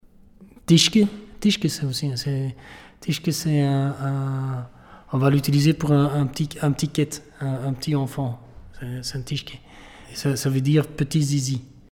prononciation Tiesjke ↘ explication Tiesjke, on va l’utiliser pour un petit 'ket’, un petit enfant.